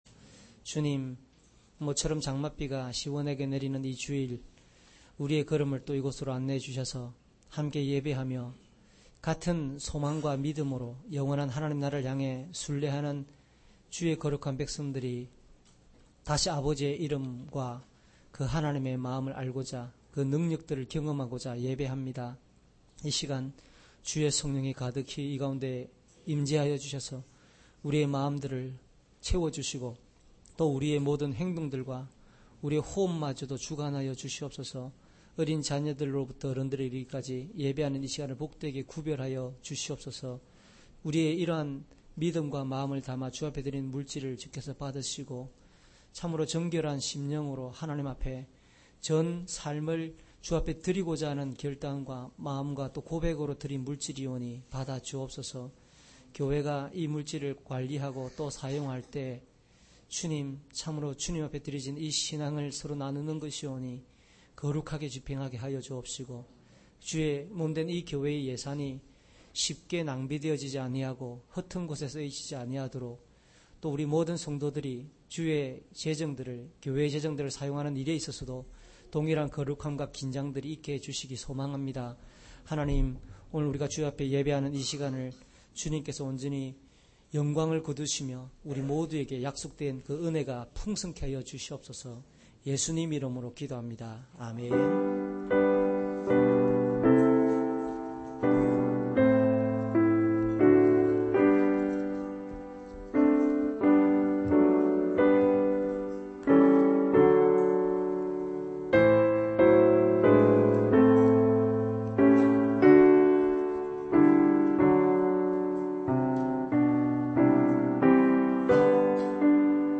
주일설교 - 10년 07월 11일 "겸손히 하늘의 지혜를 따릅시다."(눅20:27-47)